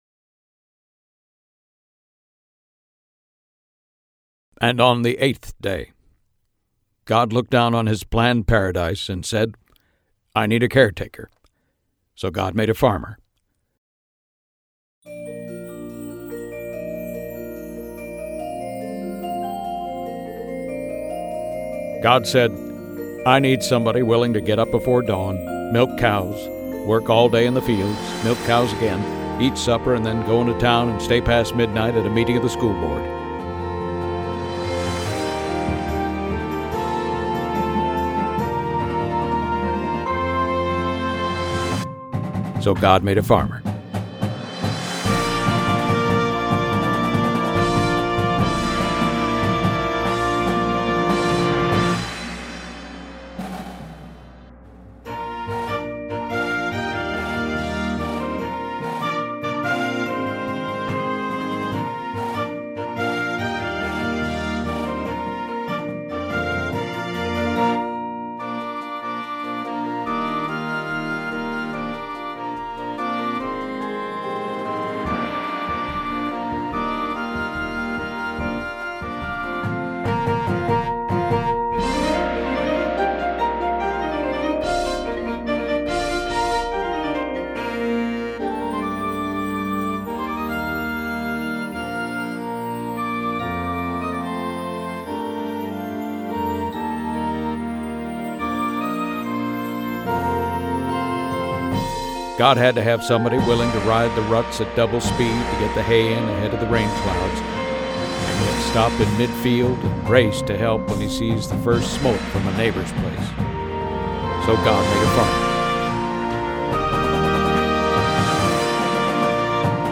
• Flute
• Clarinet 1, 2
• Alto Sax
• Trumpet 1, 2
• Horn in F
• Low Brass 1, 2
• Tuba
• Snare Drum
• Synthesizer
• Marimba 1, 2
• Glockenspiel